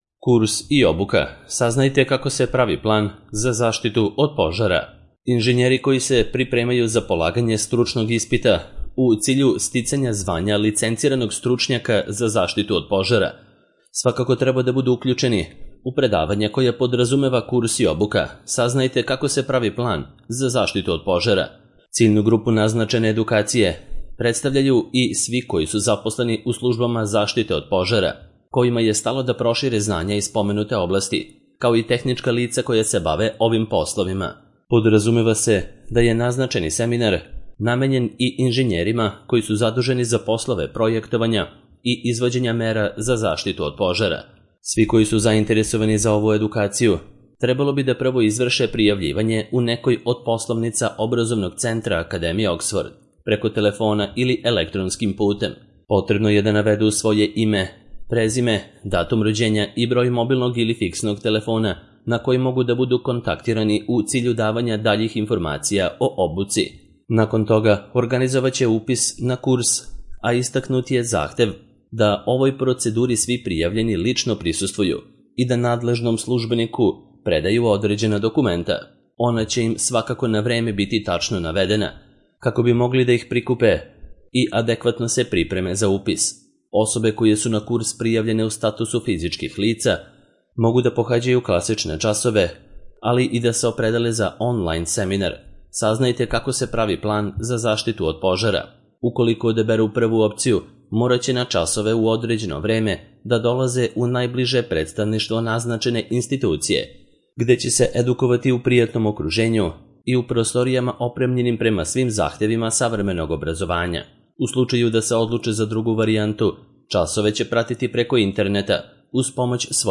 Audio verzija teksta